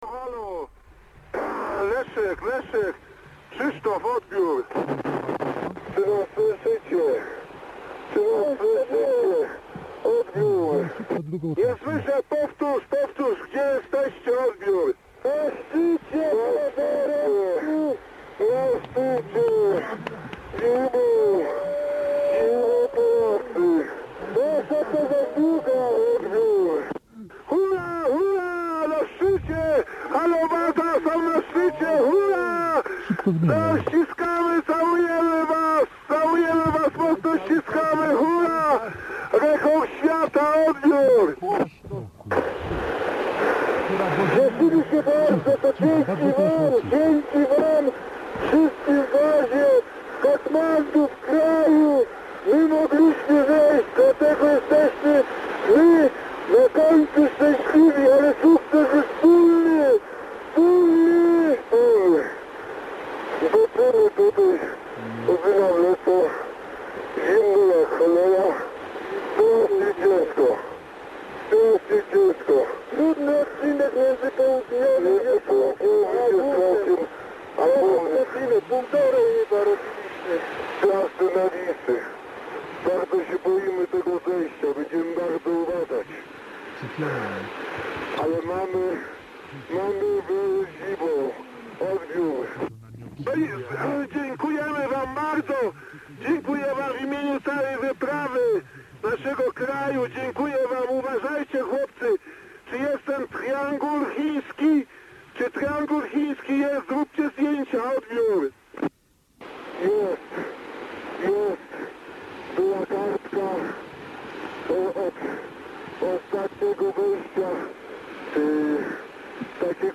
Fragment rozmowy radiowej z zimowej wyprawy na Everest - audycja PR1 "Niedziela z Mistrzem - LESZEK CICHY" 12.10.2008